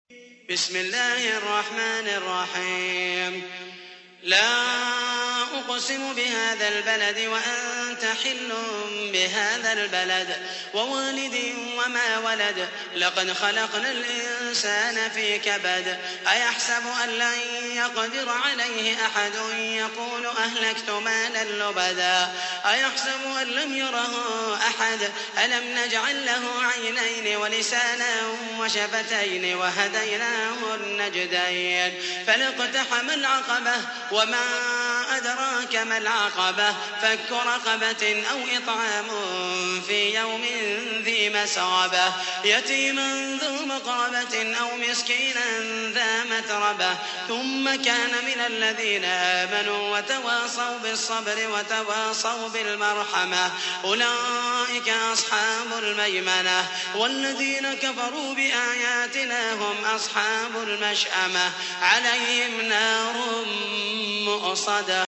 تحميل : 90. سورة البلد / القارئ محمد المحيسني / القرآن الكريم / موقع يا حسين